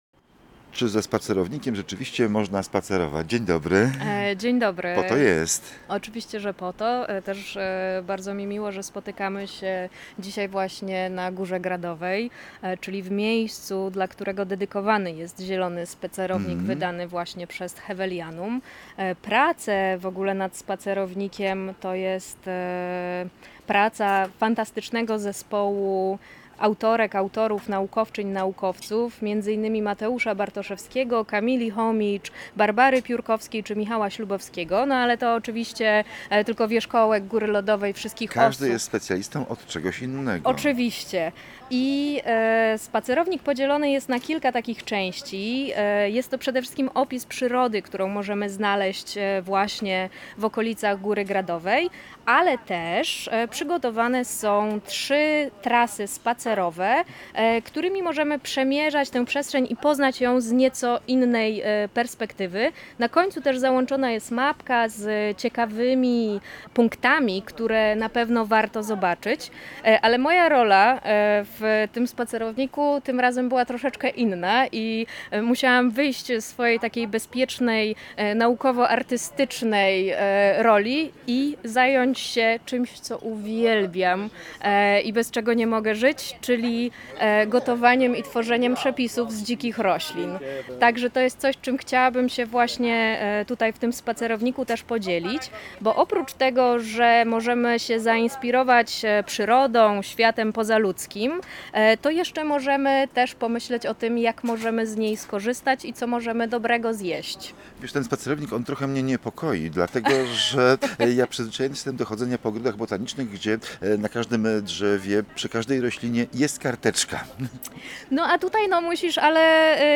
rozmawia z projektantką krajobrazu